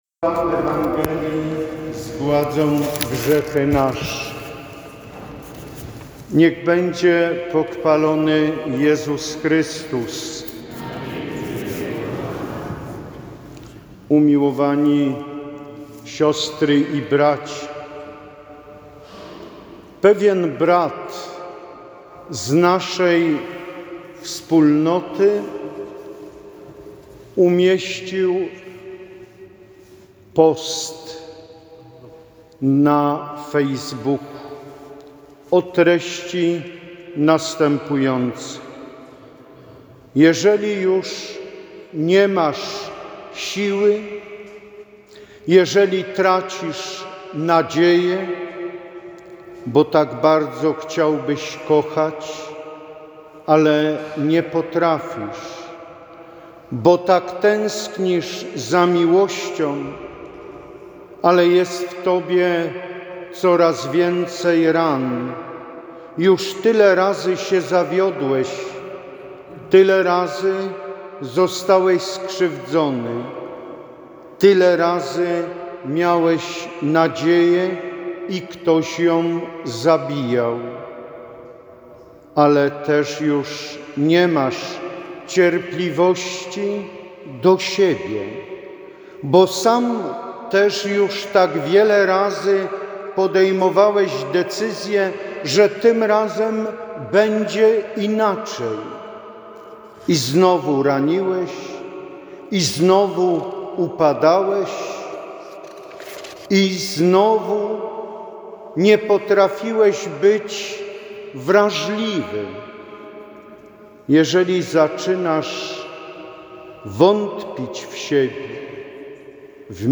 Wielka Sobota - Wigilia Paschalna - Parafia pw. św.
Wielka-Sobota-homilia.mp3